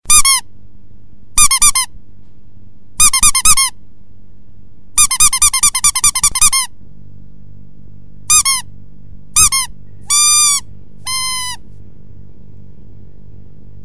На этой странице собраны звуки магазина игрушек: весёлая суета, голоса детей, звон кассы, фоновые мелодии.
Пищалка 2.0